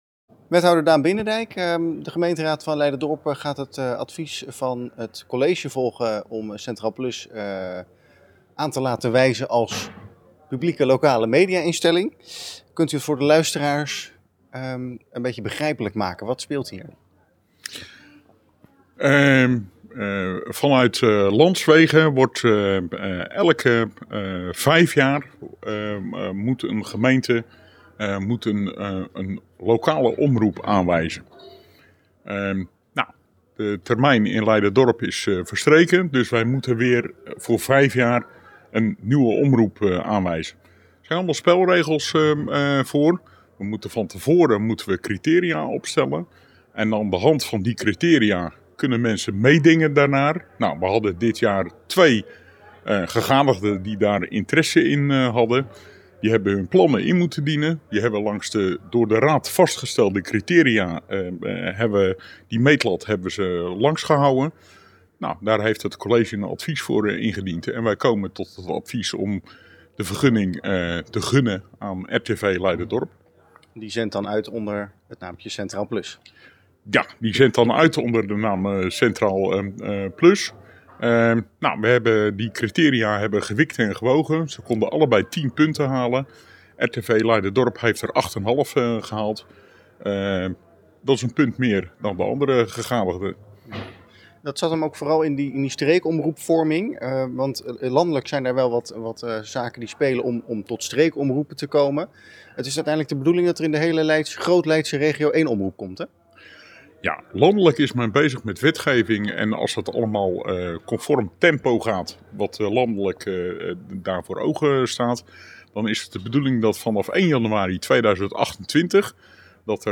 Wethouder Daan Binnendijk over de aanwijzing.
Wethouder-Daan-Binnendijk-over-omroepkeuze.mp3